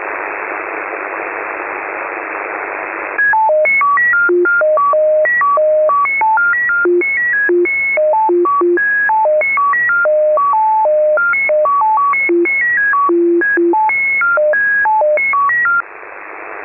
Запись радиосигнала